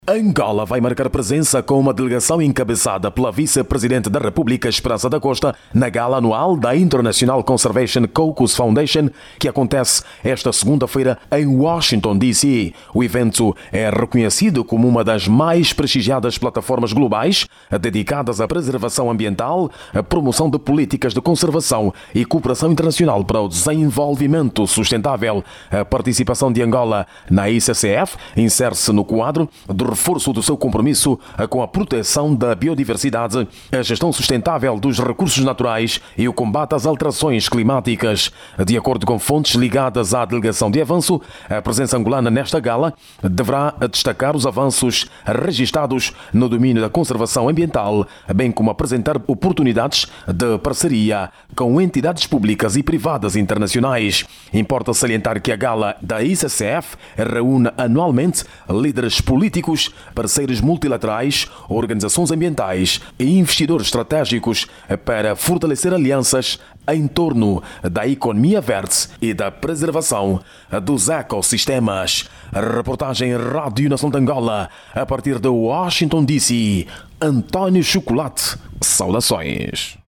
a partir de Washington: